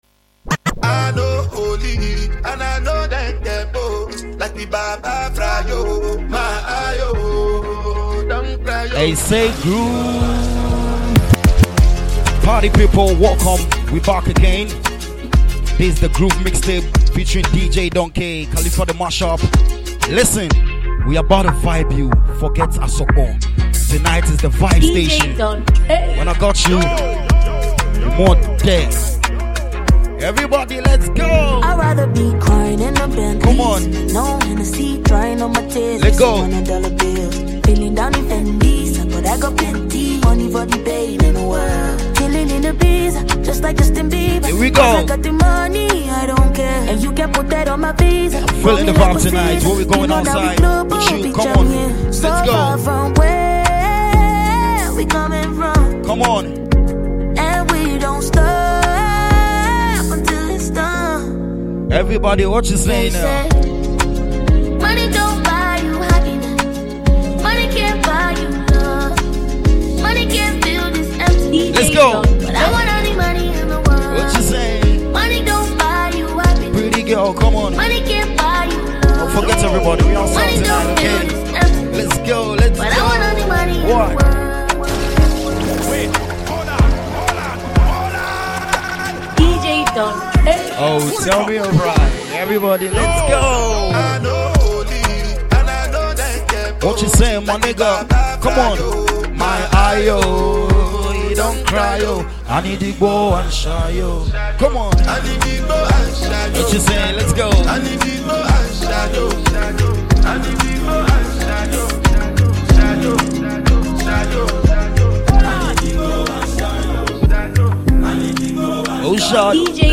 Enjoy this dope mixtape
DJ Mixtape